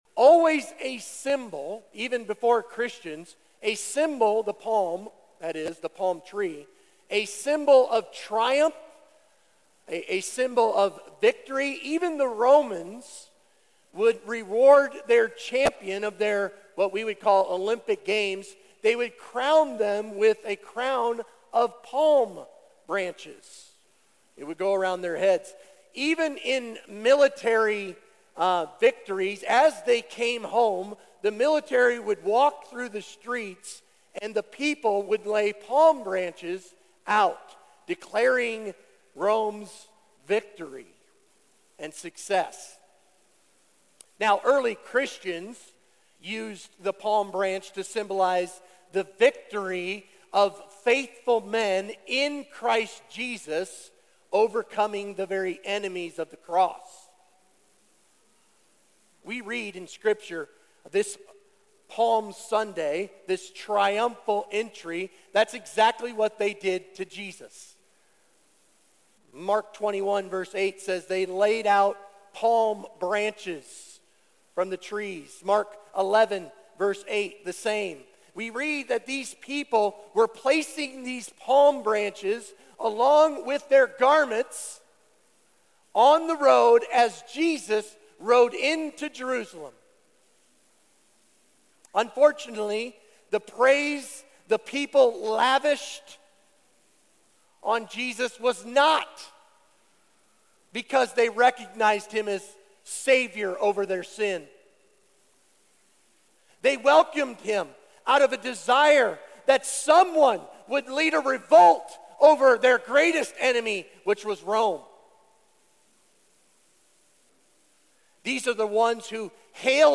Baptism Testimonies